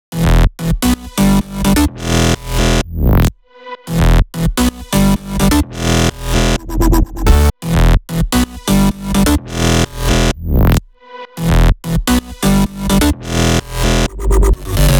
VDE 128BPM Renegade Melody Root E SC.wav